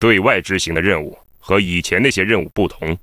文件 文件历史 文件用途 全域文件用途 Bk_tk_05.ogg （Ogg Vorbis声音文件，长度3.0秒，108 kbps，文件大小：40 KB） 源地址:游戏语音 文件历史 点击某个日期/时间查看对应时刻的文件。